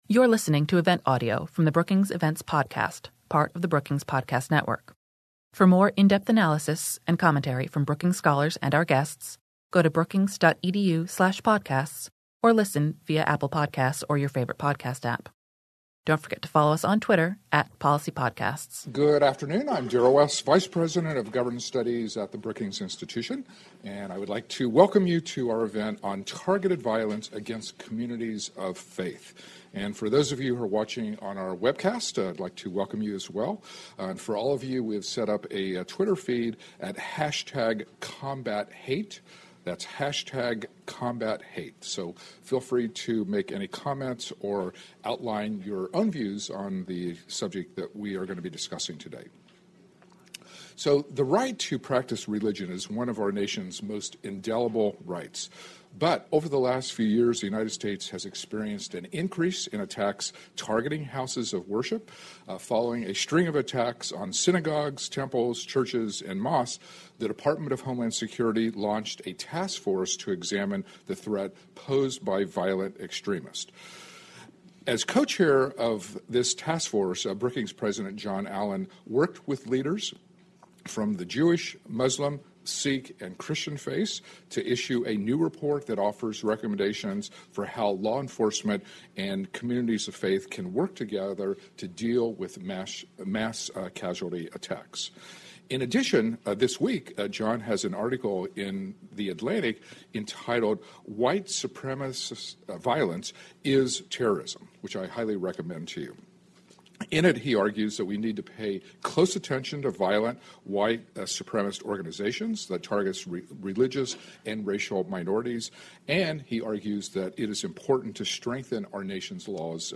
After the panel, speakers answered questions from the audience. https